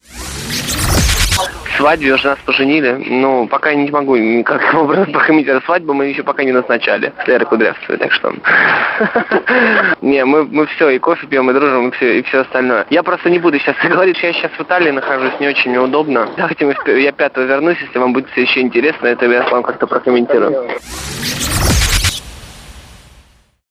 Наши корреспонденты тут же позвонили Лазареву, чтобы случайно не пропустить свадьбу певца…
Слушать комментарии Сергея